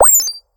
gain_xp_04.ogg